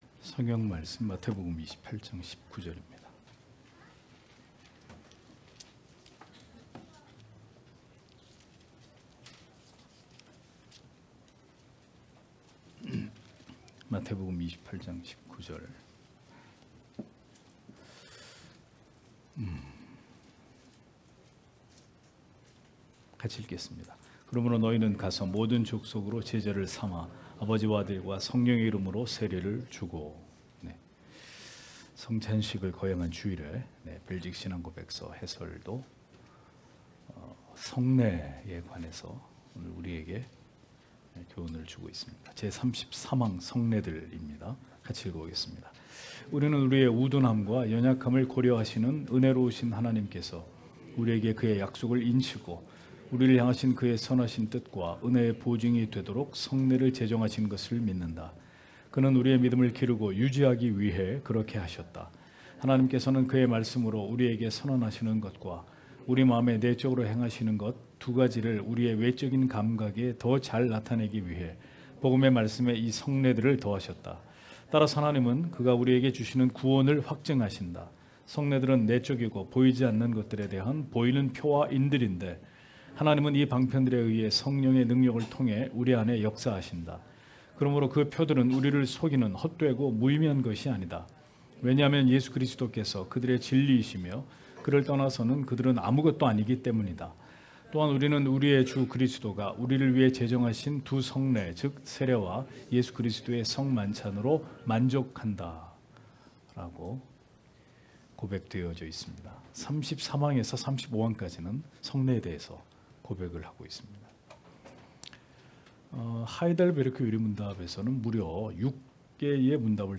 주일오후예배 - [벨직 신앙고백서 해설 38] 제33항 성례들 (마 28장19절)